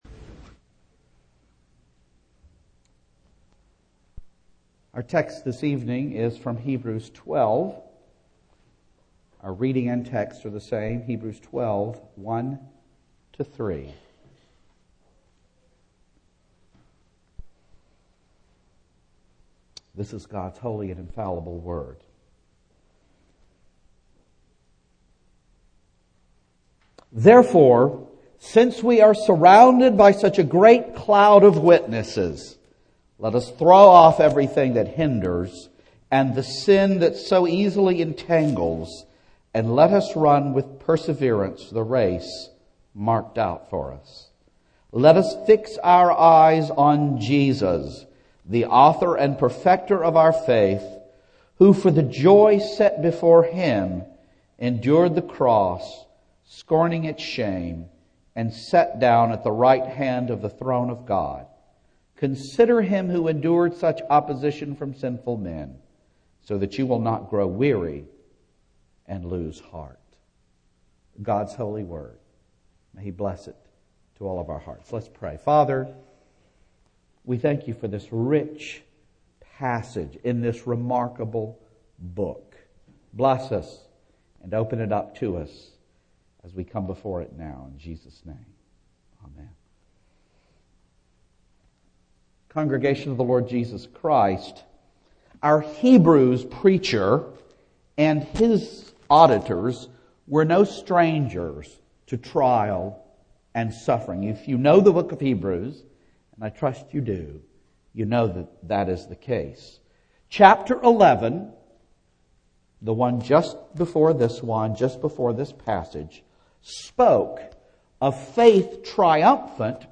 Single Sermons
Service Type: Evening